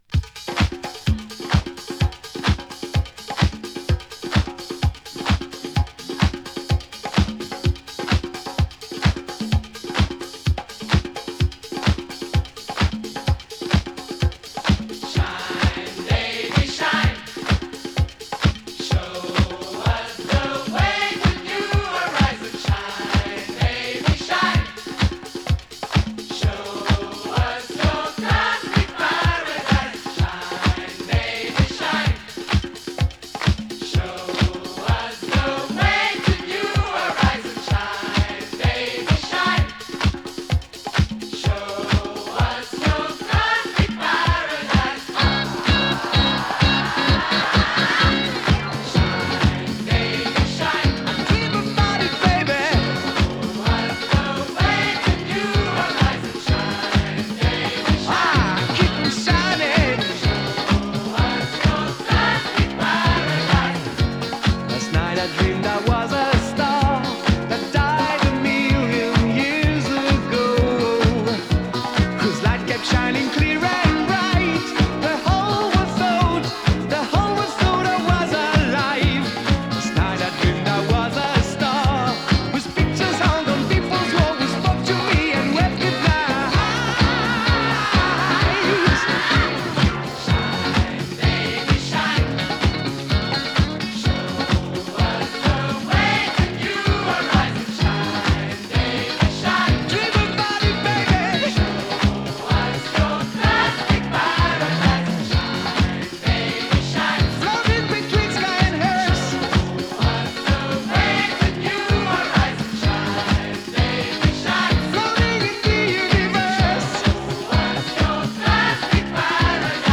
ディスコ サンバ